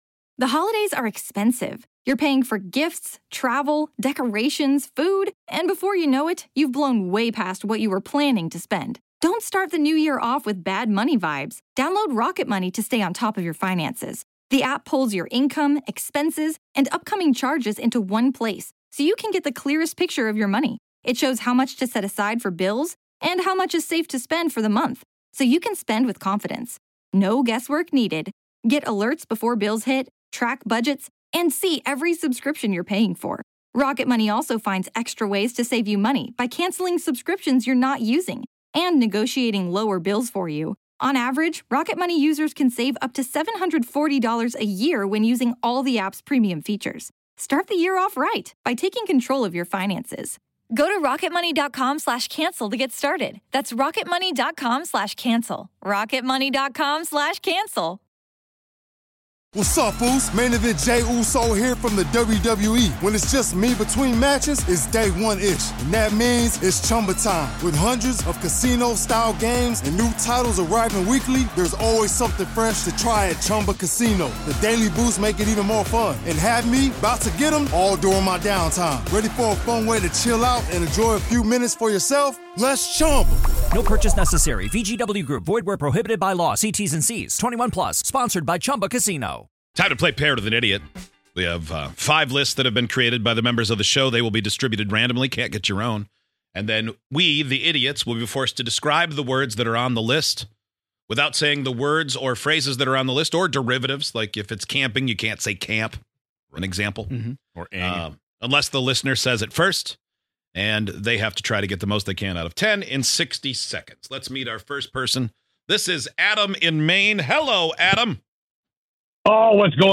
On today's show, we each paired up with a listener and had another show members personalised list. Could you correctly describe and guess all 10 items on the list?